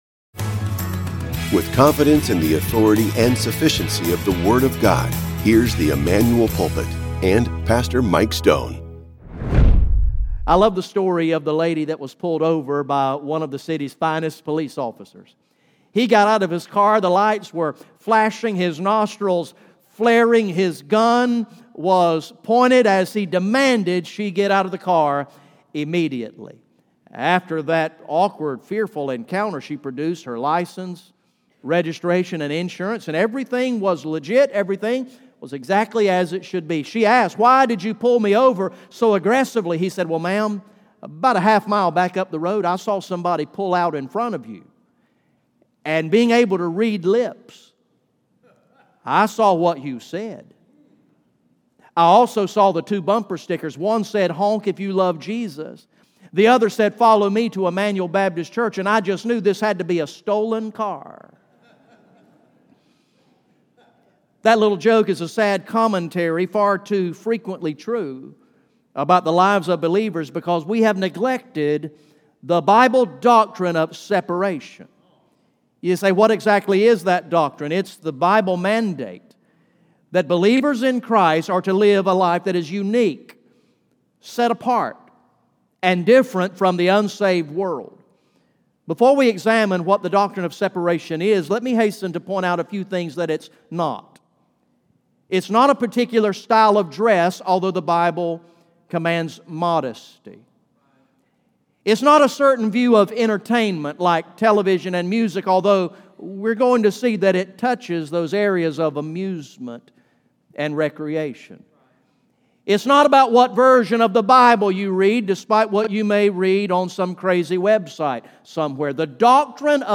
GA Sunday AM